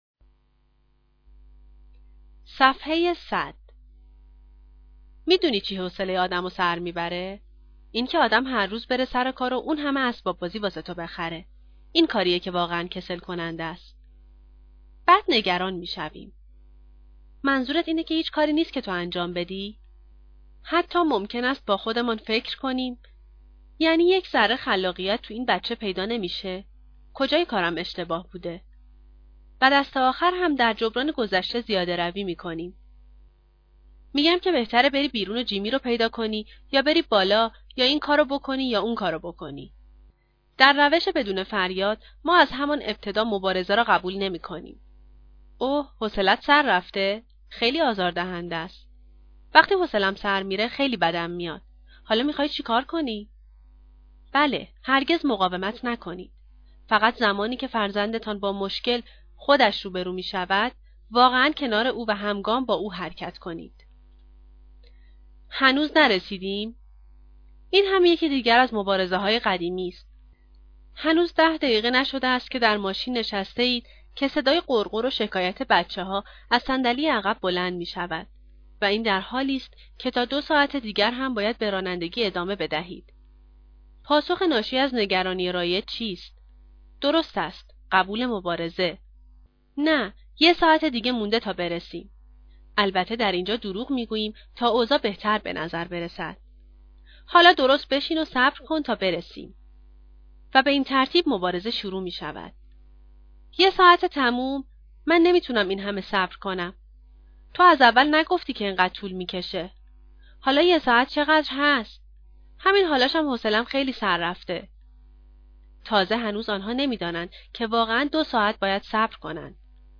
Tarbiate-Bedune-Faryad-Audio-Book-7.mp3